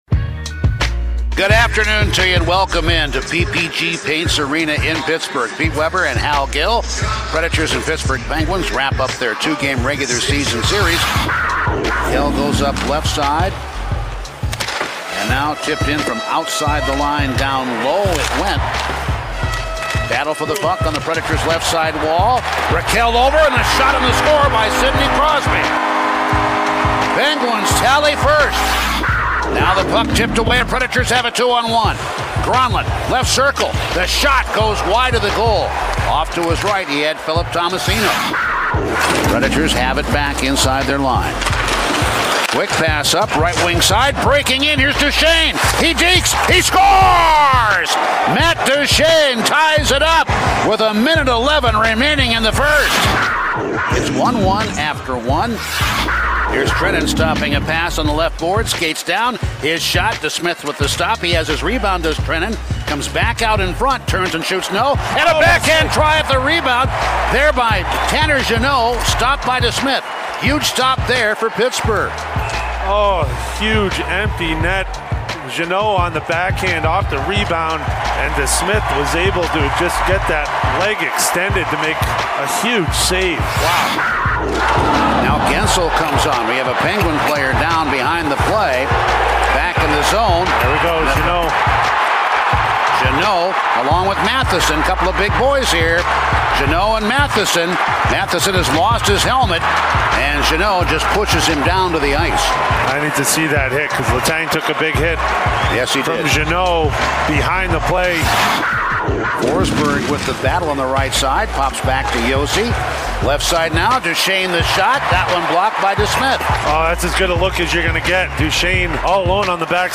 Radio highlights from the Preds' 3-2 overtime loss in Pittsburgh